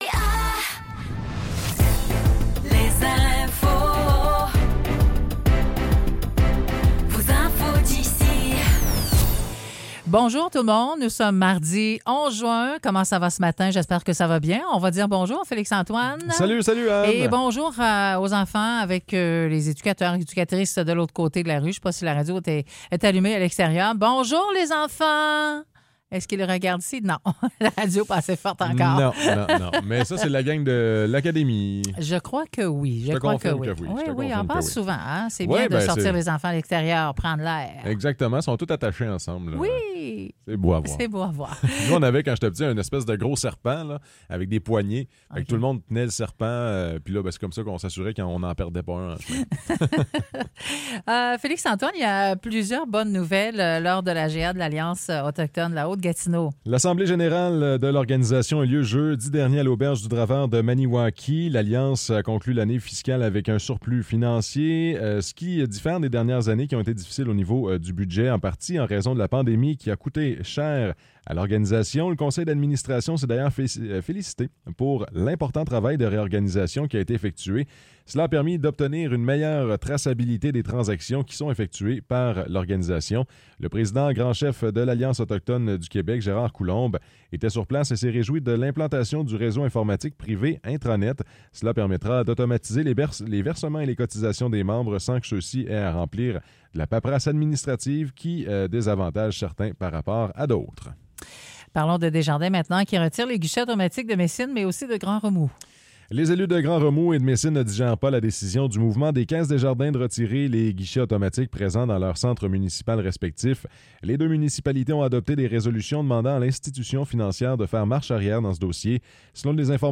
Nouvelles locales - 11 juin 2024 - 9 h